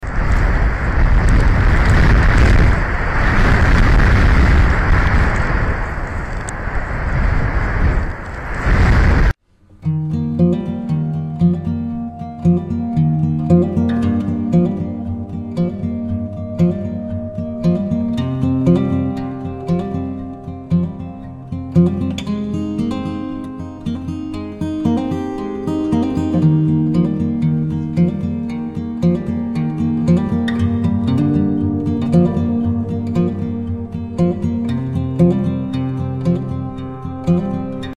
Jackie and Mp3 Sound Effect Braving the storm: Jackie and Shadow, the renowned bald eagle pair of Big Bear Valley, protect their new eaglets amidst 80-100 mph 7.752 winds and heavy snow.